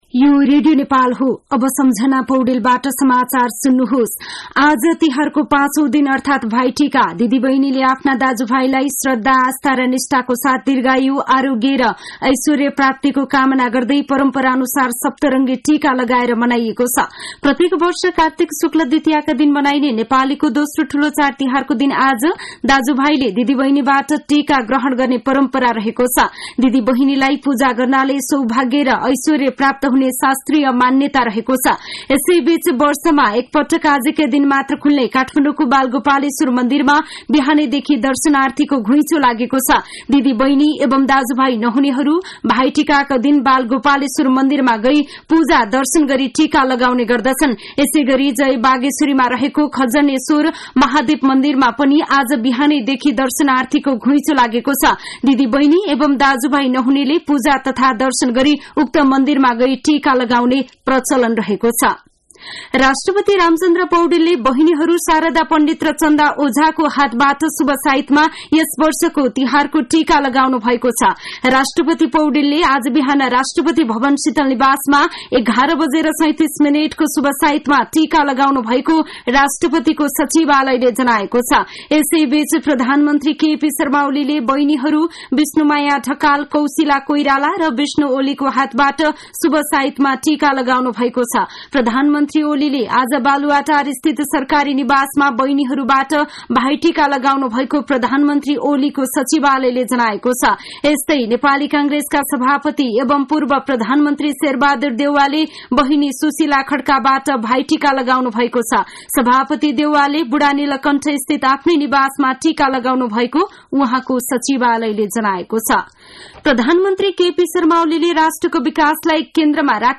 साँझ ५ बजेको नेपाली समाचार : १९ कार्तिक , २०८१